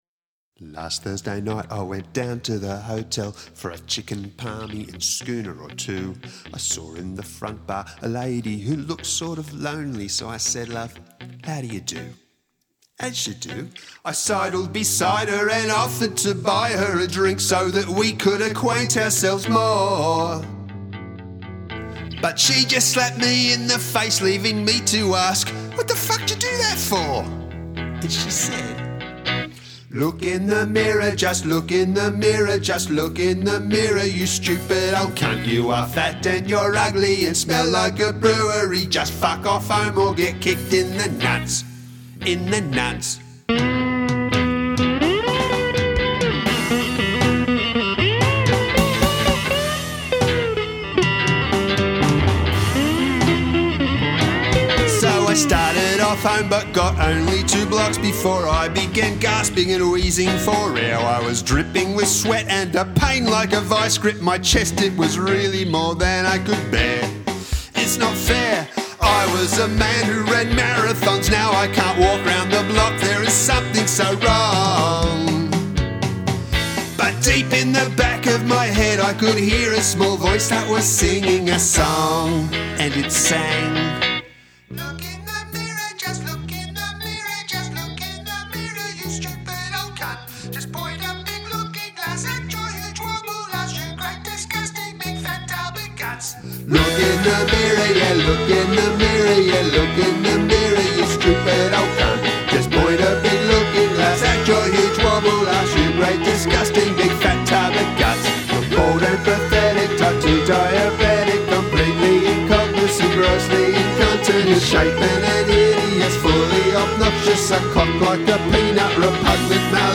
Waltz